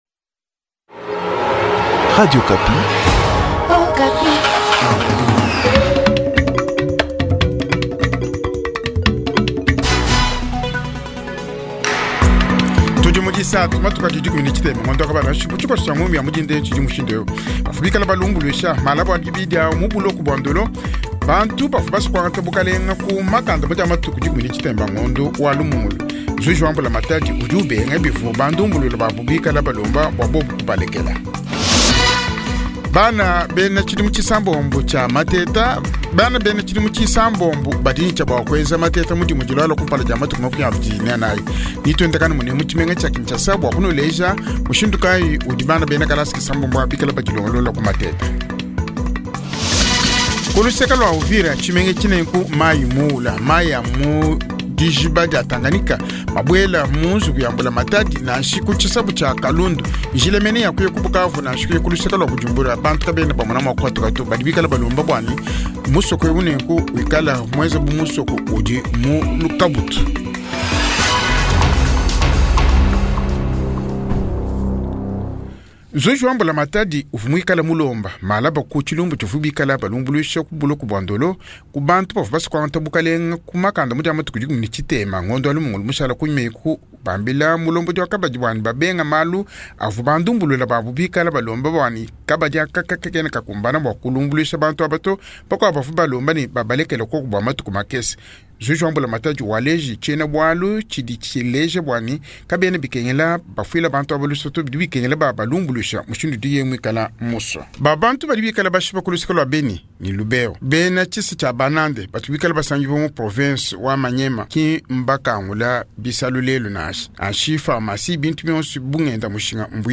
Journa Tshiluba